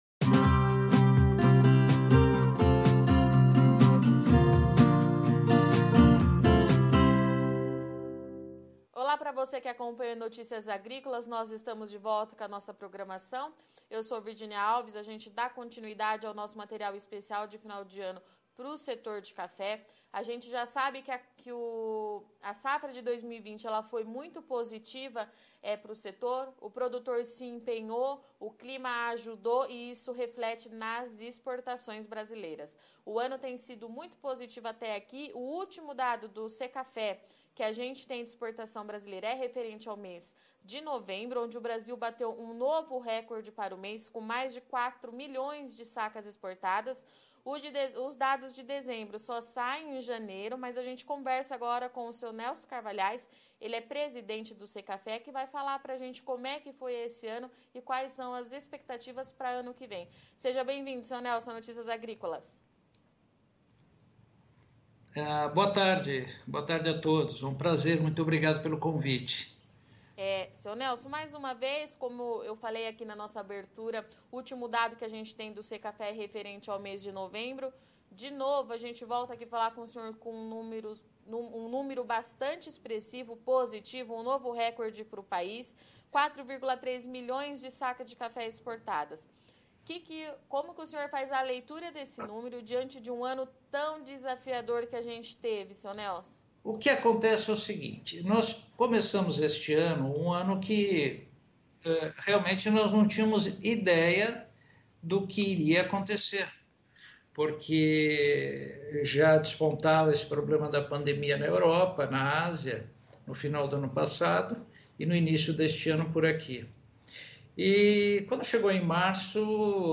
entrevista ao Notícias Agrícolas